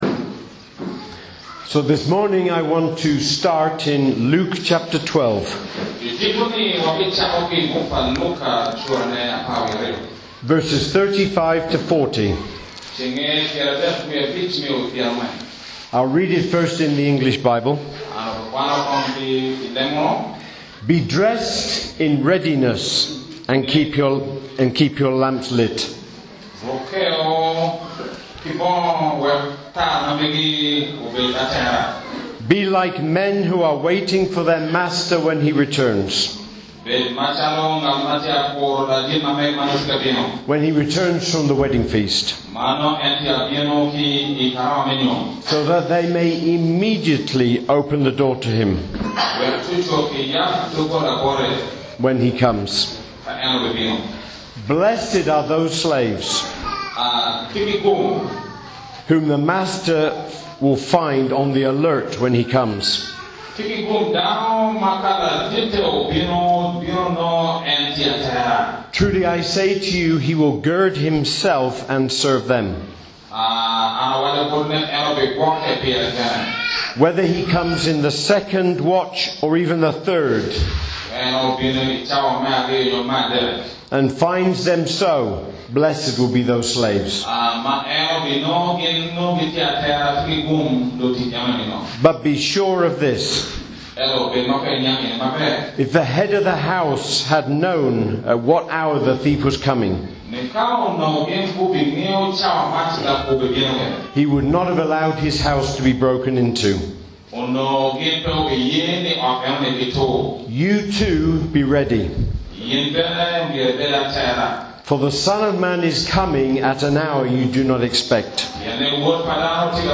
Recorded at Called to Serve Church, Kitgum, Uganda on Sunday 19th May 2024.